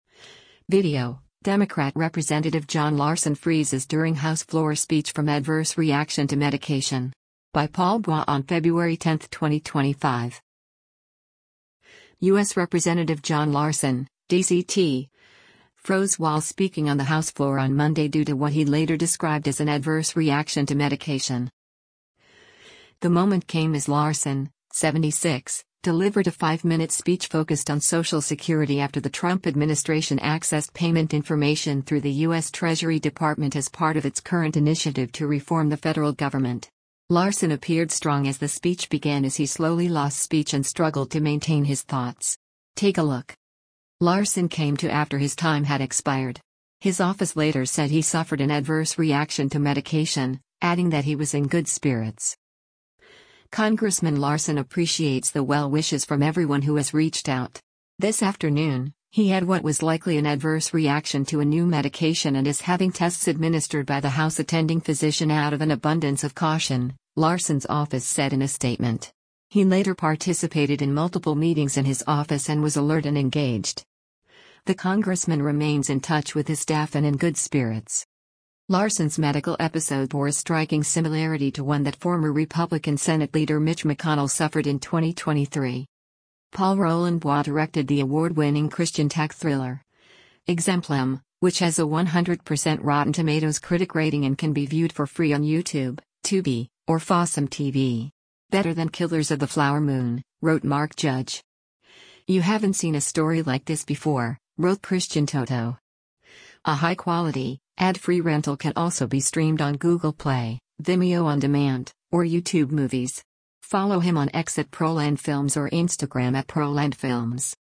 Video: Democrat Rep. John Larson Freezes During House Floor Speech from ‘Adverse Reaction’ to Medication
U.S. Rep. John Larson (D-CT) froze while speaking on the House floor on Monday due to what he later described as an “adverse reaction” to medication.
The moment came as Larson, 76, delivered a five-minute speech focused on Social Security after the Trump administration accessed payment information through the U.S. Treasury Department as part of its current initiative to reform the federal government. Larson appeared strong as the speech began as he slowly lost speech and struggled to maintain his thoughts.